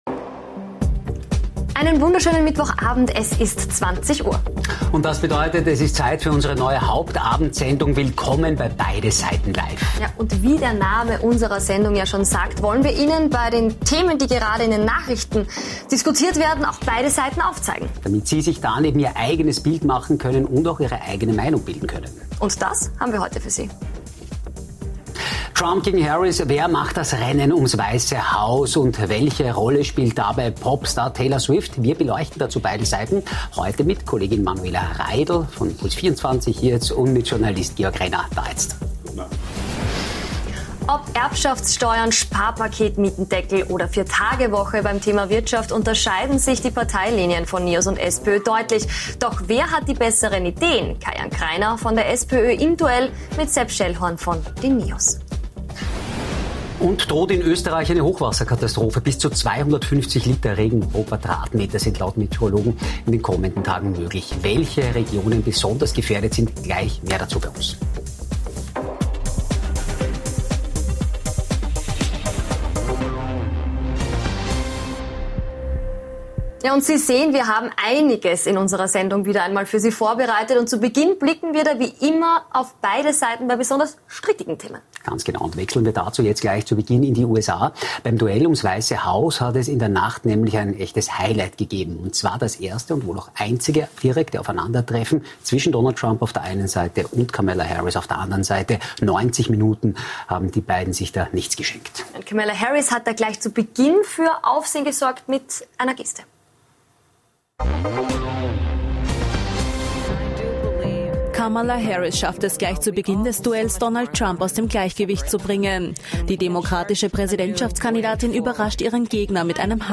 Im Duell: Kai Jan Krainer (SPÖ) vs. Sepp Schellhorn (NEOS) ~ Beide Seiten Live Podcast
Und nachgefragt haben wir heute bei gleich zwei Gästen - im großen Beide Seiten Live Duell mit Kai Jan Krainer, SPÖ und Sepp Schellhorn, NEOS.